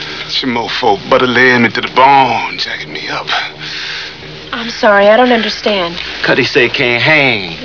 airplane1.wav